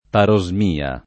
parosmia [ paro @ m & a ]